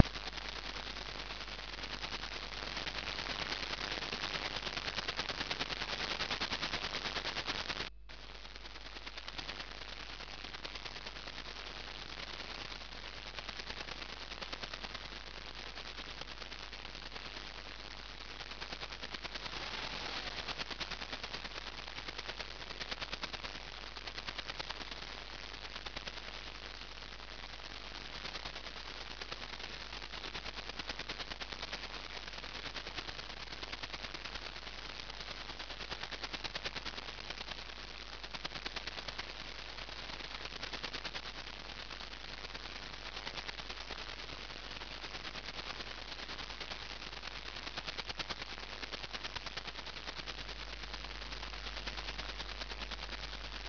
Баблер (глушилка) на КВ